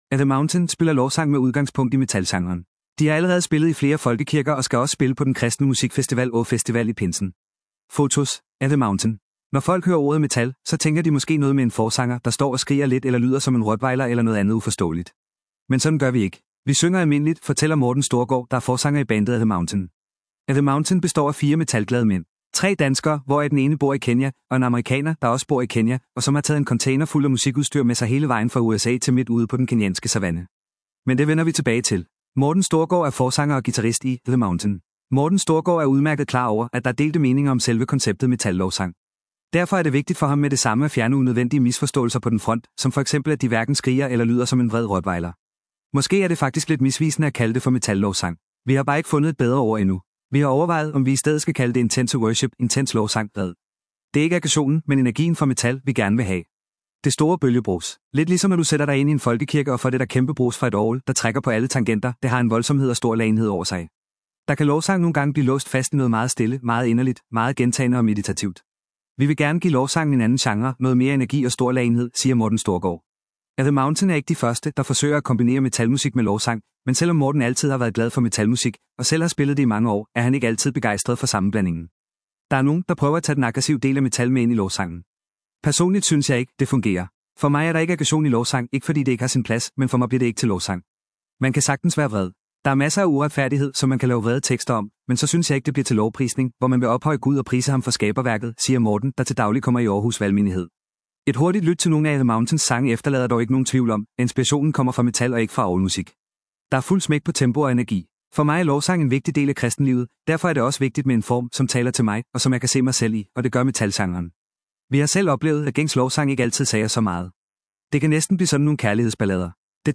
spiller lovsang med udgangspunkt i metalgenren
Der er fuld smæk på tempo og energi.